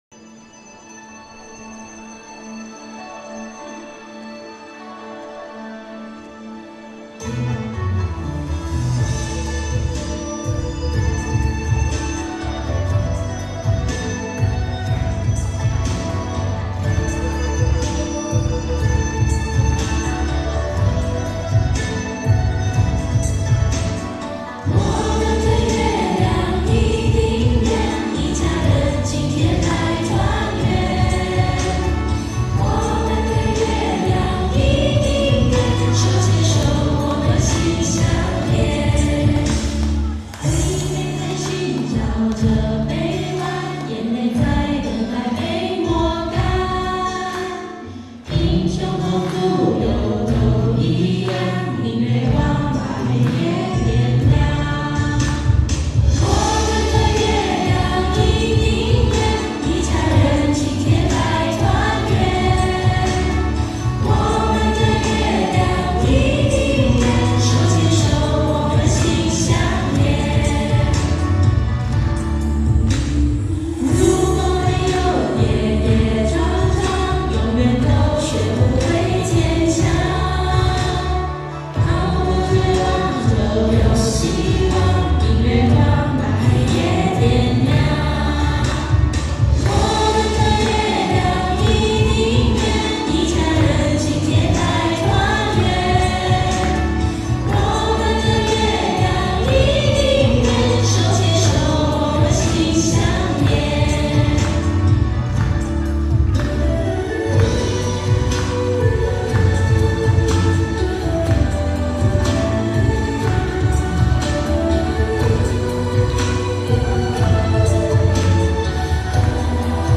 音频：欢庆中秋、新加坡佛友庆中秋合唱“我们的月亮一定圆、我们永远心相连” 一曲一天堂、一素一菩提！